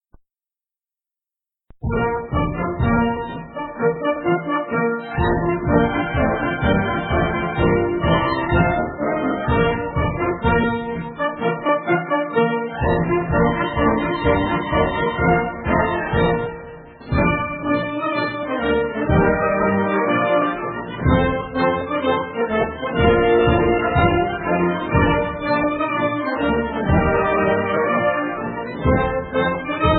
Mirari ² - Fichier "fanfare.mp3"